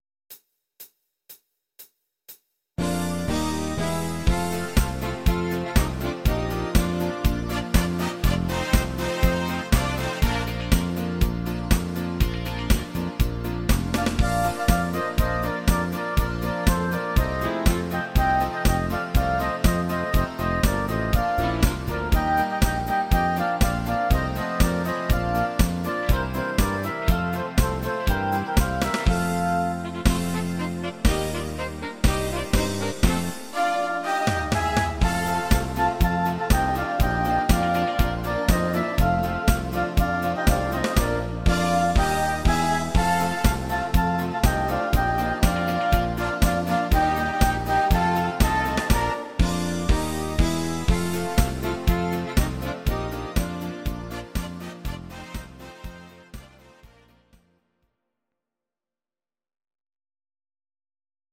Audio Recordings based on Midi-files
German, Duets, Volkstï¿½mlich